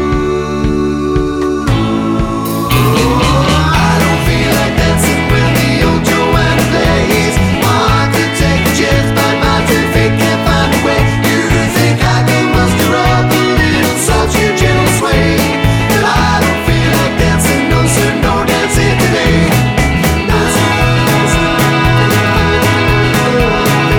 no Backing Vocals Rock 'n' Roll 4:08 Buy £1.50